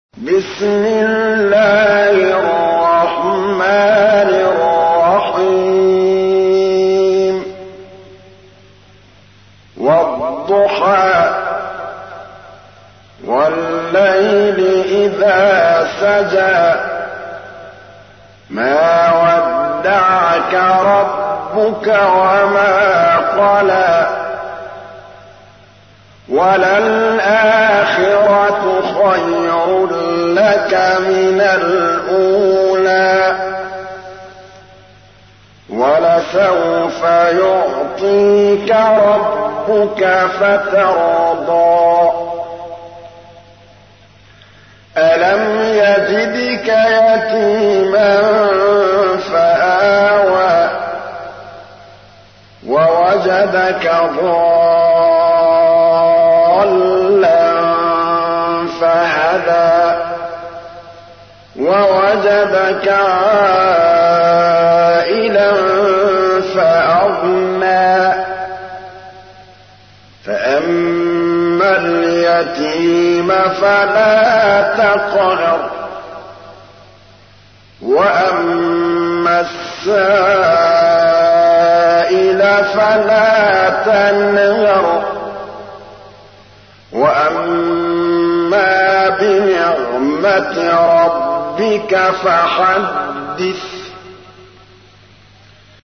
تحميل : 93. سورة الضحى / القارئ محمود الطبلاوي / القرآن الكريم / موقع يا حسين